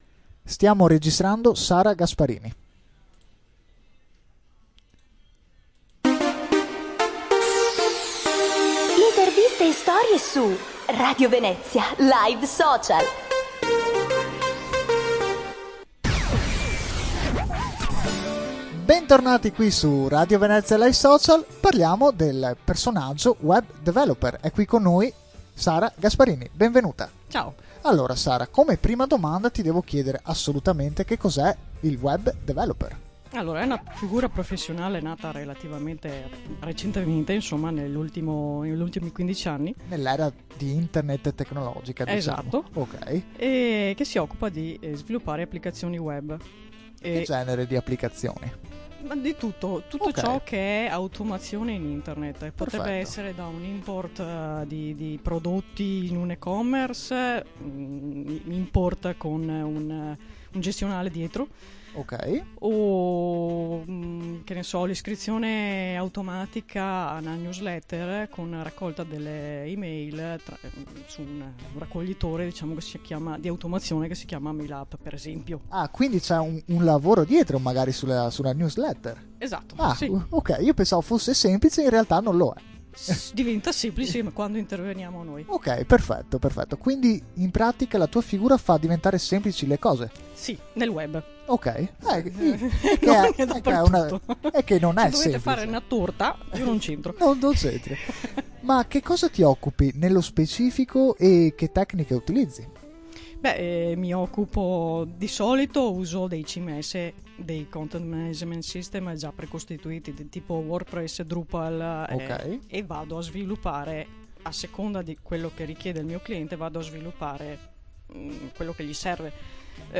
Intervista su Radio Venezia
Il 28 agosto 2017, quindi non proprio ieri, ho dovuto affrontare il nervosismo di parlare al microfono per una registrazione di un programma di Radio Venezia. Non è mai facile descrivere il proprio lavoro agli altri.